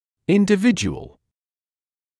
[ˌɪndɪˈvɪdʒuəl][ˌиндиˈвиджуэл]индивидуальный; человек, индивидуум